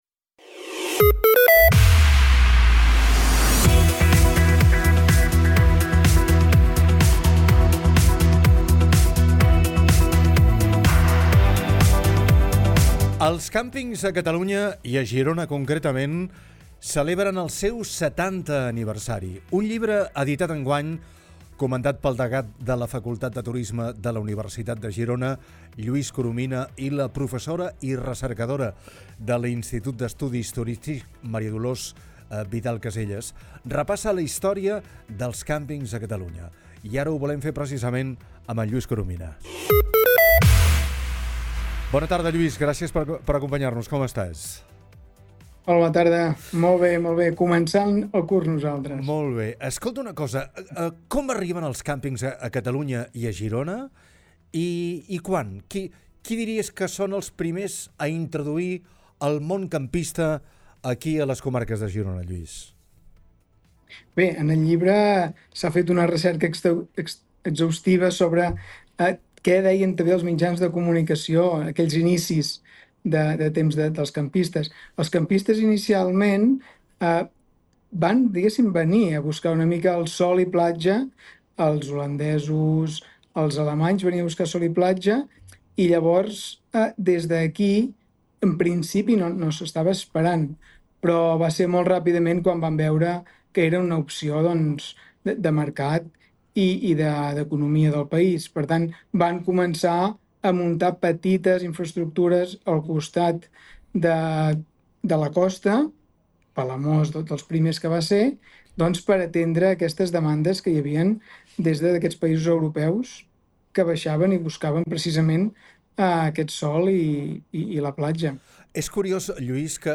entrevistat